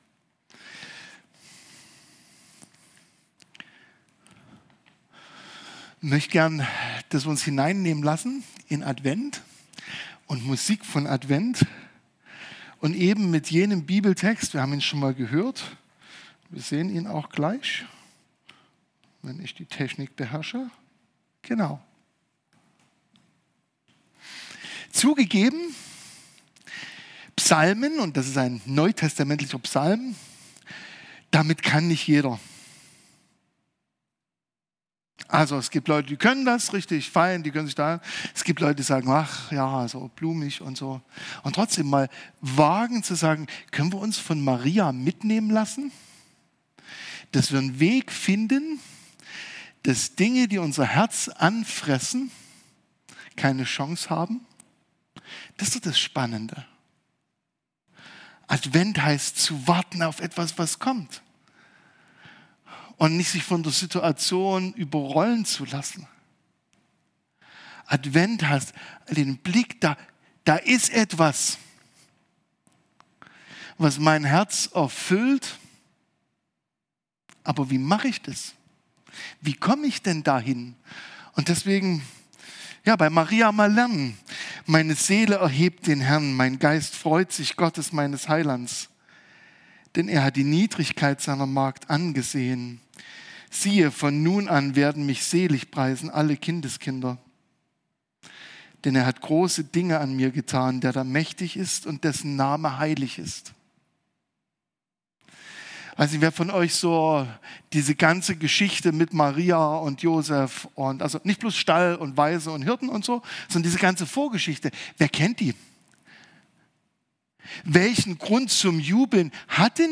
Musikalischer Gottesdienst am 3. Advent – auch wenn dir vielleicht gerade gar nicht zum Singen zumute ist? Genauso ging es auch Maria, als sie von der großen Aufgabe erfuhr, für die Gott sie auserwählt hatte. Aber was könnte passieren, wenn du dich Gott zur Verfügung stellst, so wie Maria es tat?